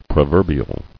[pro·ver·bi·al]